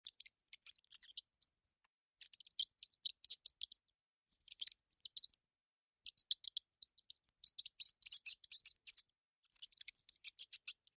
Скрип и шорох мышей в темном подвале